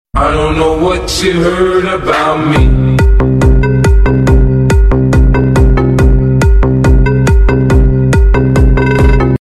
Chocolate cover little strawberry asmr sound effects free download